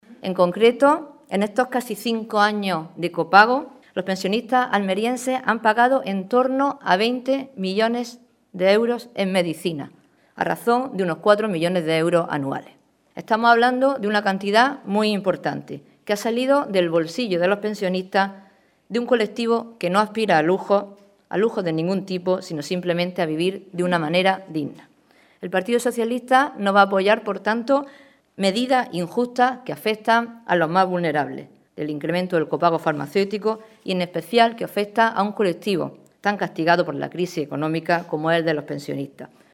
Rueda de prensa sobre copago farmacéutico que ha ofrecido la diputada nacional socialista Sonia Ferrer acompañada del diputado nacional Juan Jiménez y el senador socialista Juan Carlos Pérez Navas